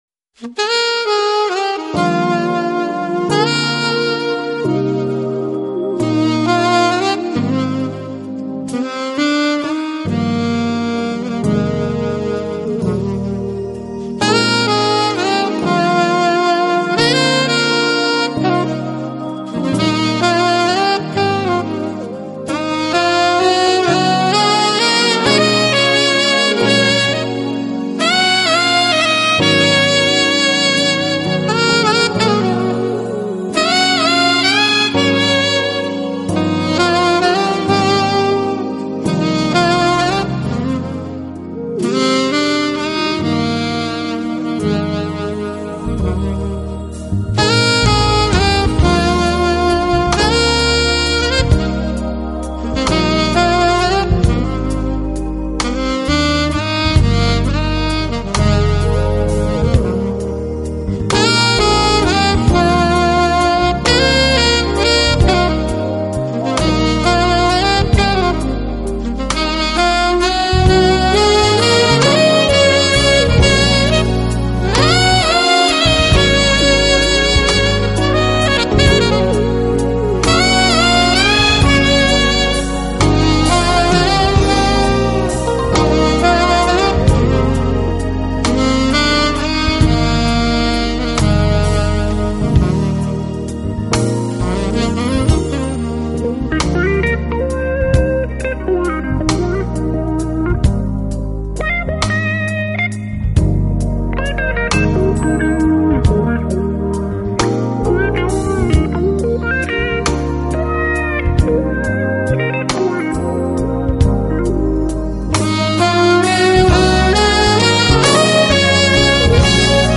音乐风格：Instrumental/Jazz